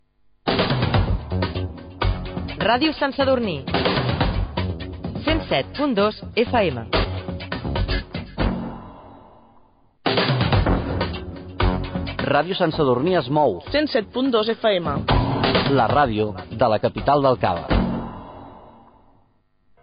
Dos indicatius de l'emissora